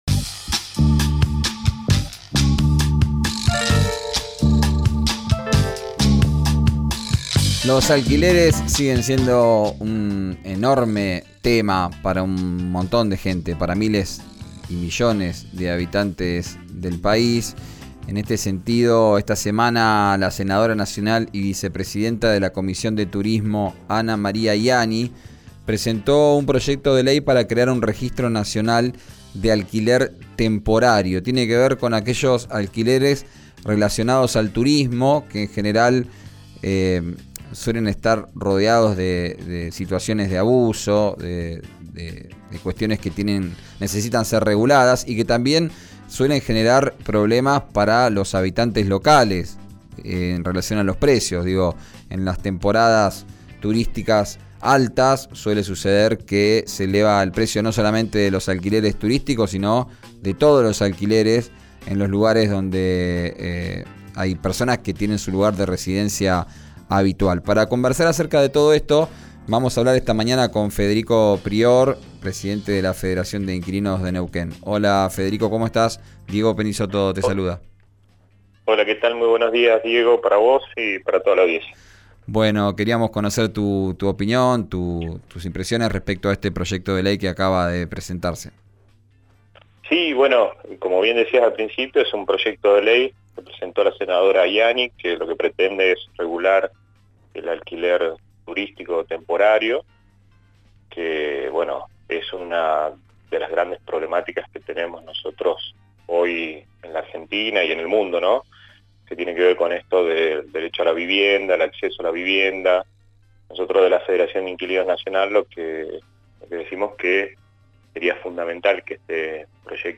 en declaraciones a «Arranquemos», por RÍO NEGRO RADIO, explicó la importancia de contar con reglamentación para no vulnerar los derechos de los que alquilan.